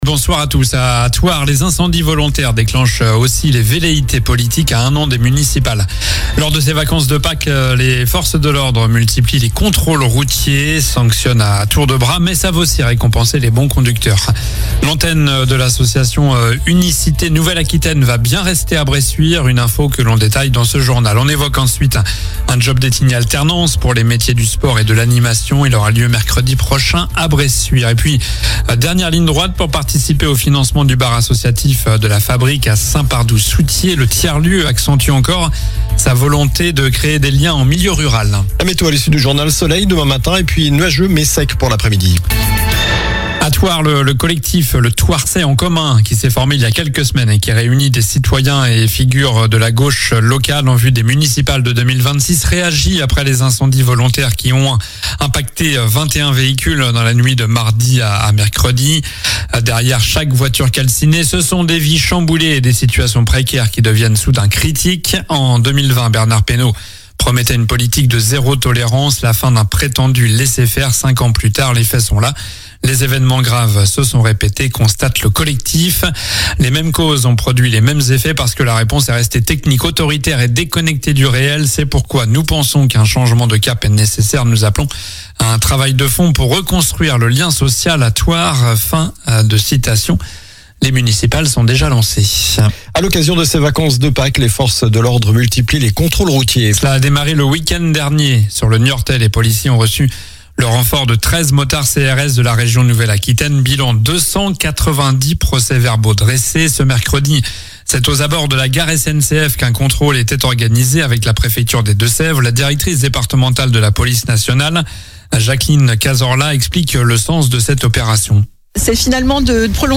Journal du jeudi 24 avril (soir)